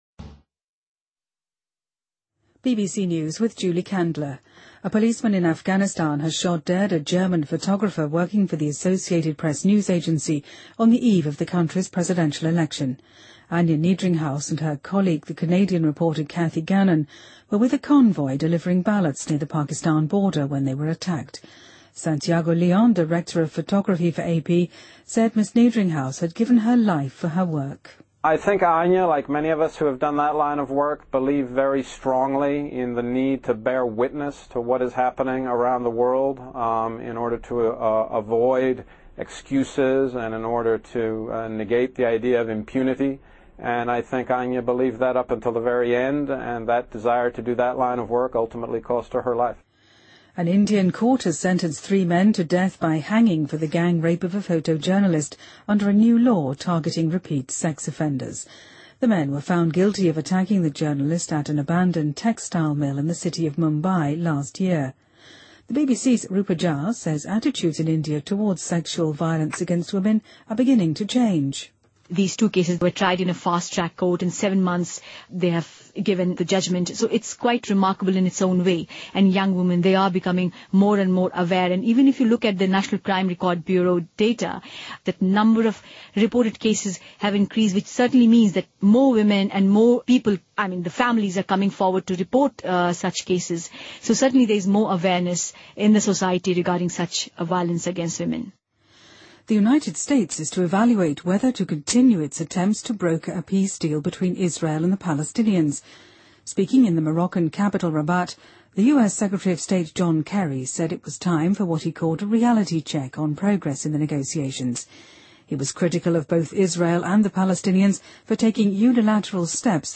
BBC news,2014-04-05